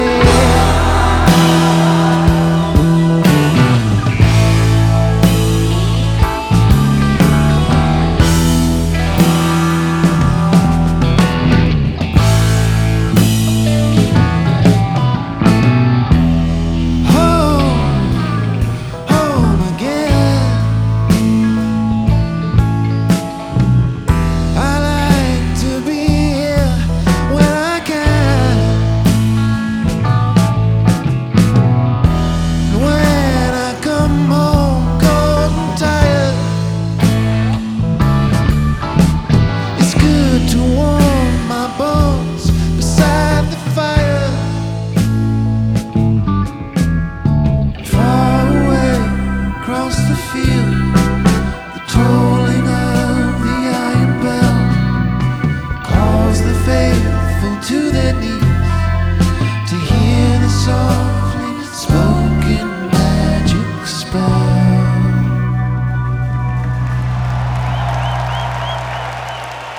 Genre : Rock.